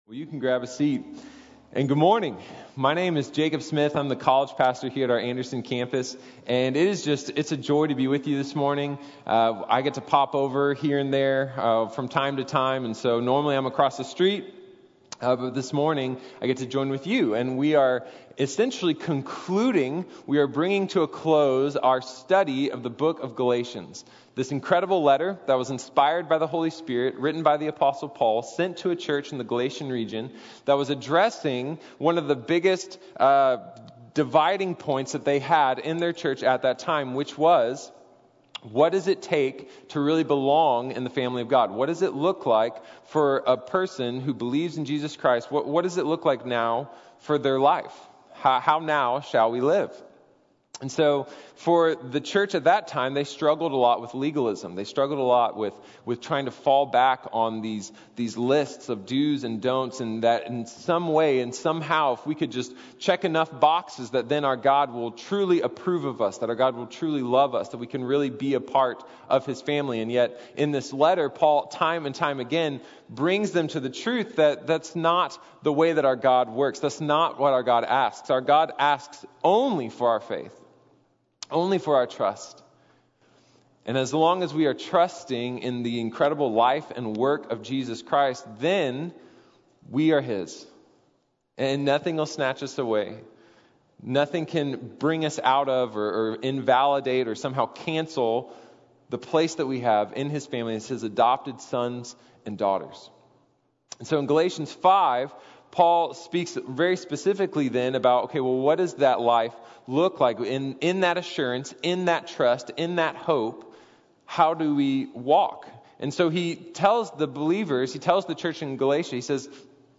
This is the Way | Sermon | Grace Bible Church